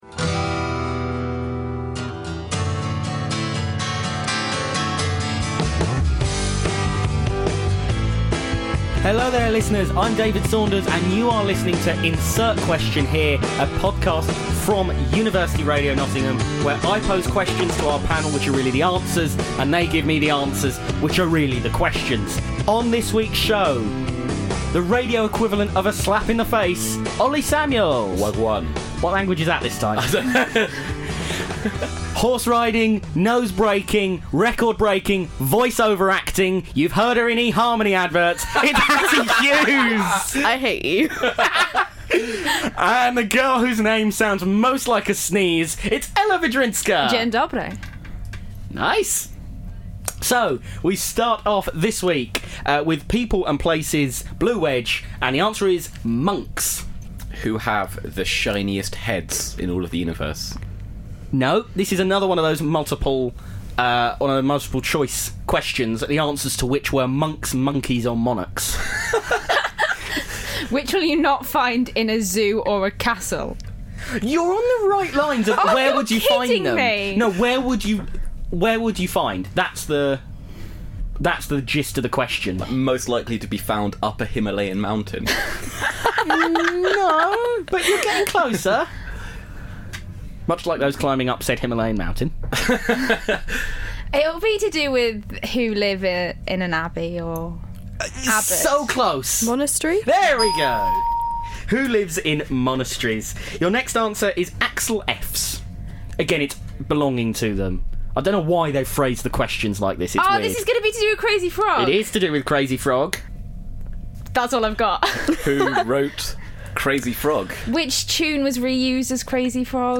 4 students. 1 pack of children's Trivial Pursuit cards.